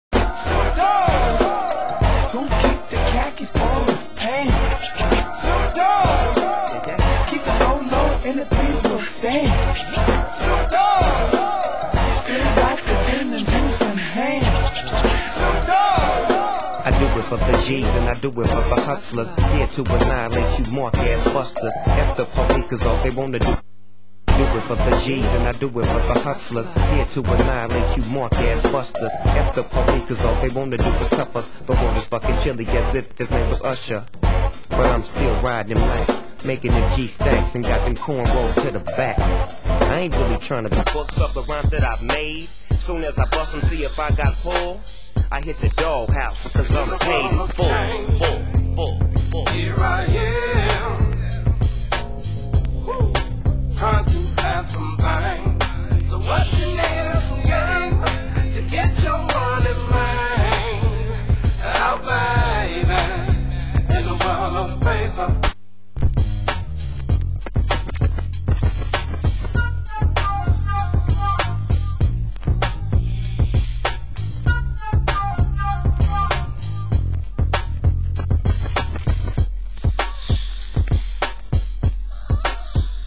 Format: Vinyl 12 Inch
Genre: Hip Hop